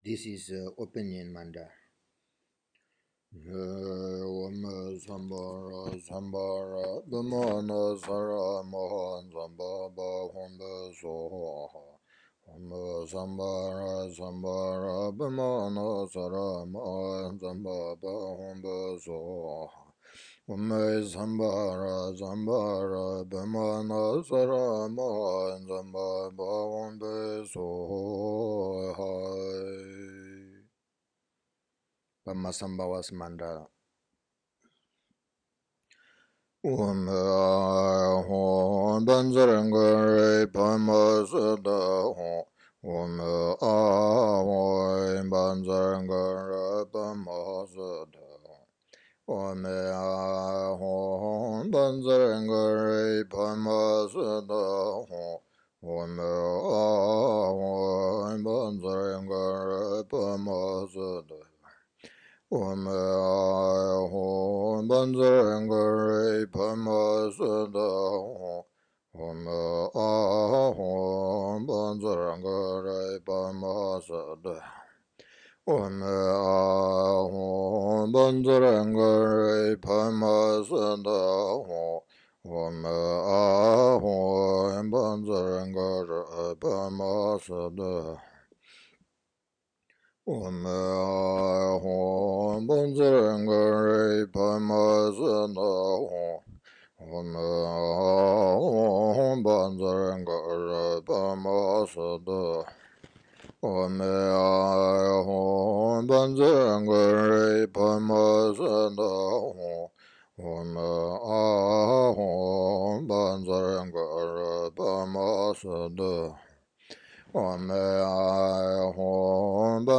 Preliminary-Mantras-1.m4a